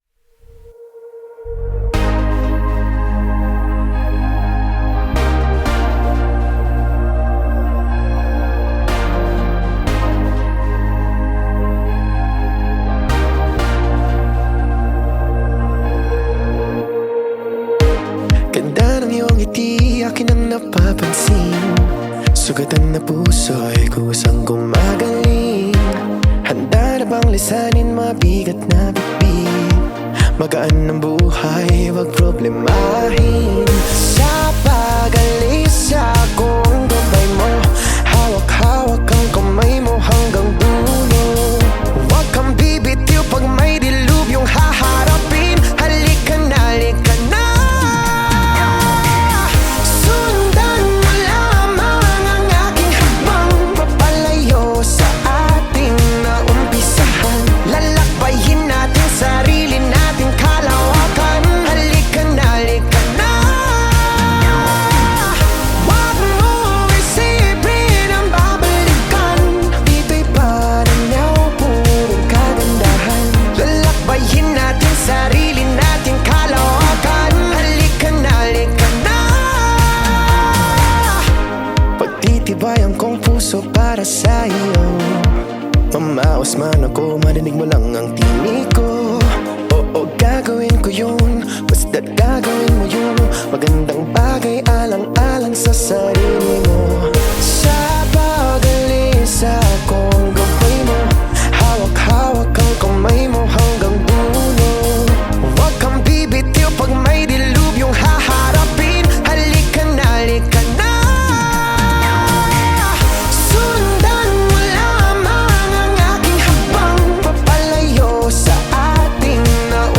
uplifting song